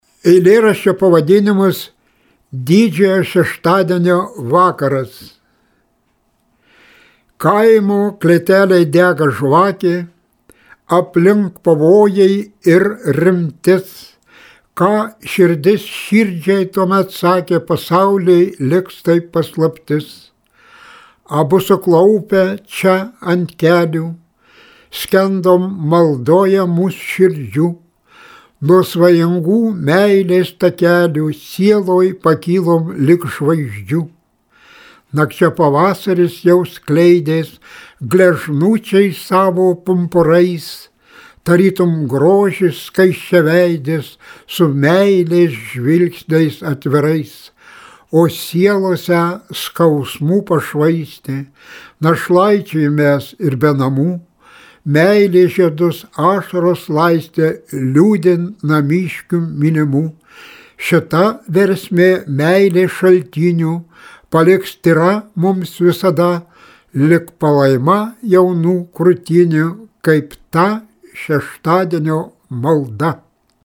Eilės
eilių, jo paties skaitomų